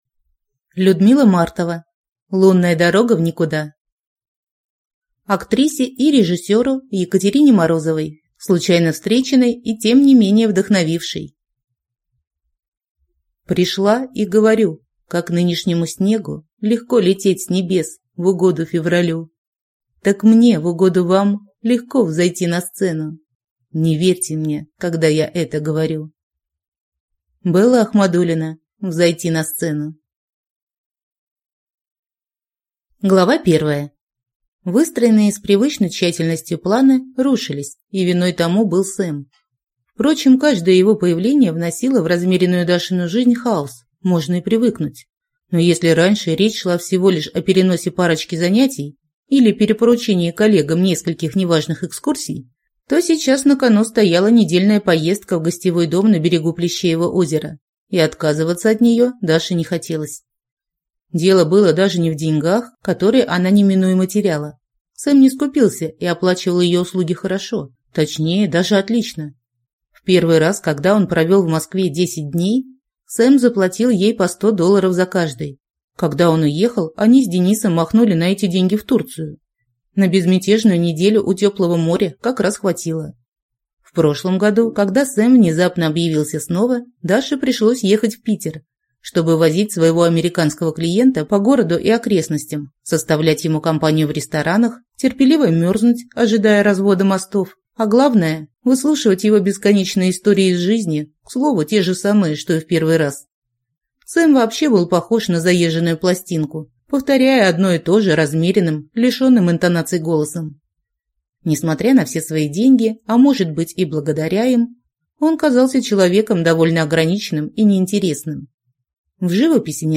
Аудиокнига Лунная дорога в никуда | Библиотека аудиокниг